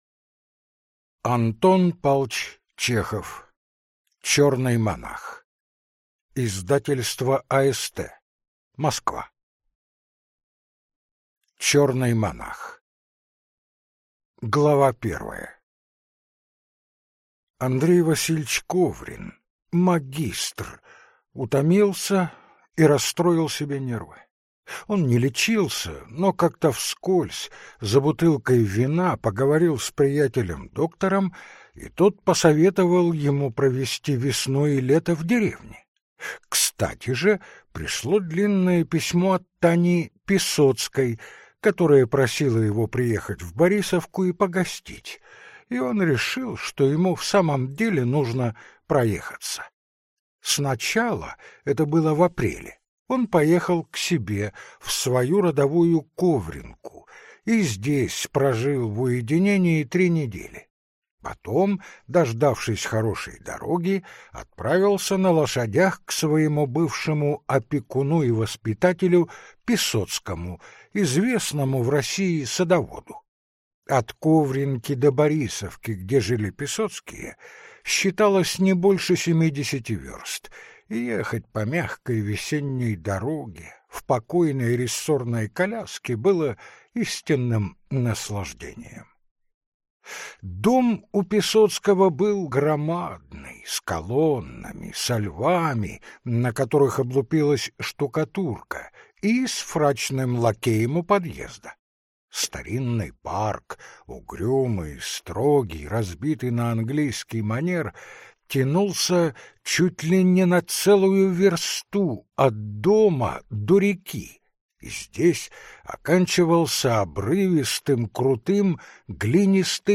Аудиокнига Чёрный монах | Библиотека аудиокниг